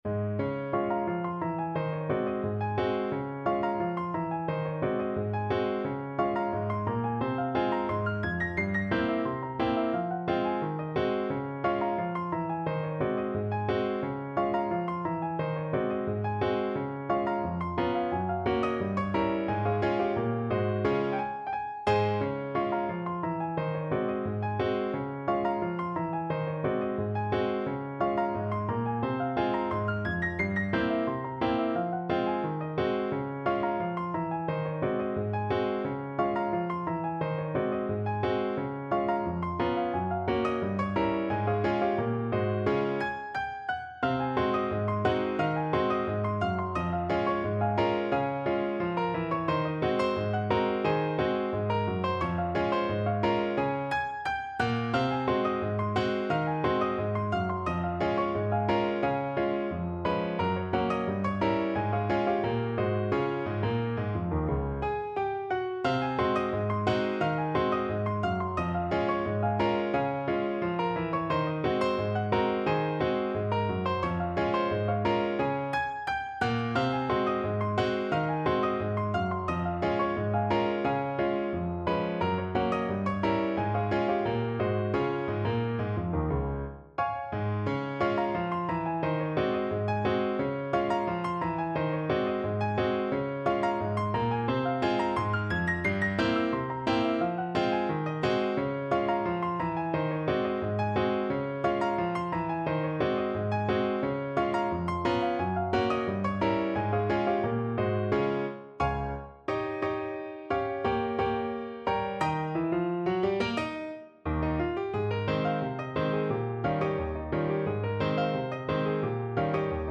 Piano version
No parts available for this pieces as it is for solo piano.
~ = 88 Not fast
2/4 (View more 2/4 Music)